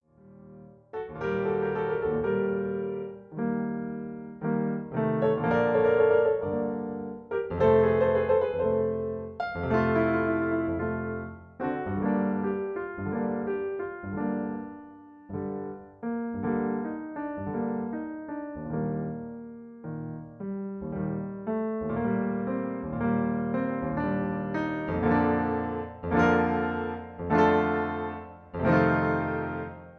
MP3 piano accompaniment